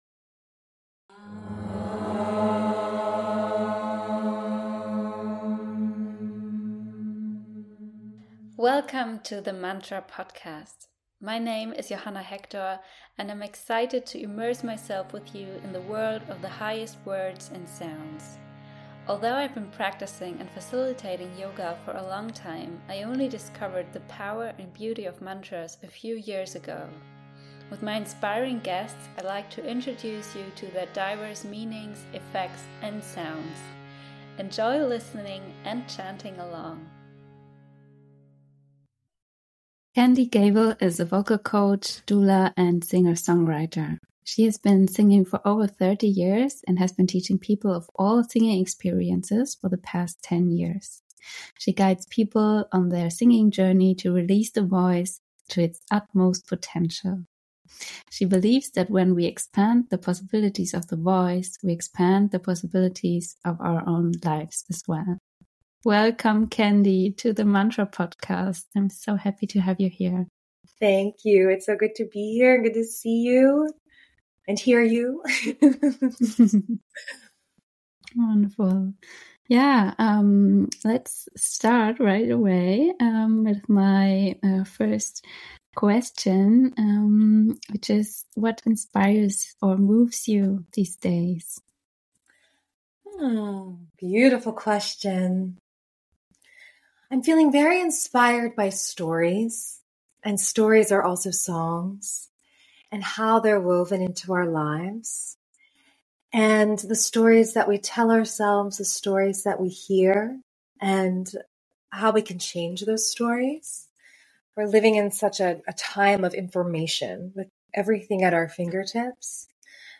An inspiring and heart-warming conversation about how stories shape our life, the joy of singing from the heart and the power of using mantras during pregnancy, giving birth and raising children.